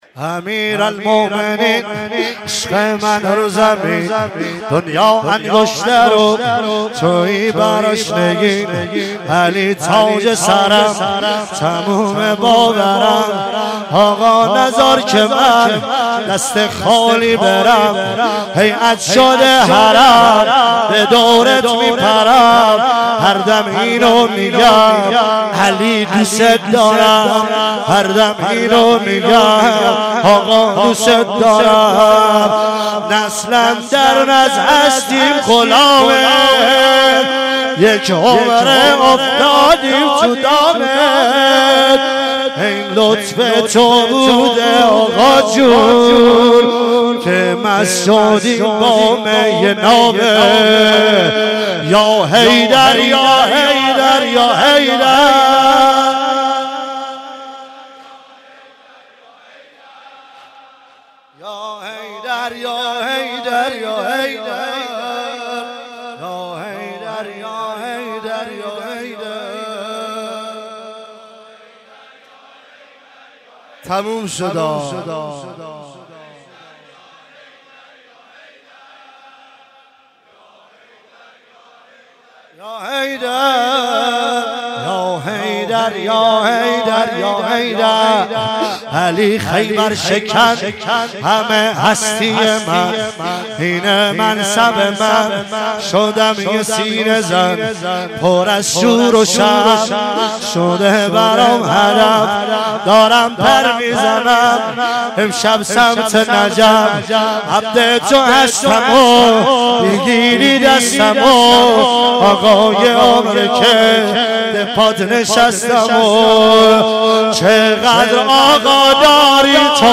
مراسم فاطمیه اول ۹۶
واحد تند